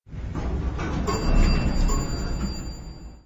Beep.ogg